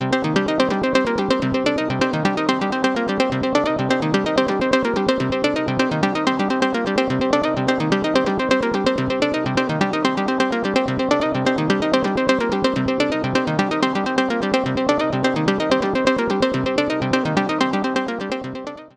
The synth loop un effected.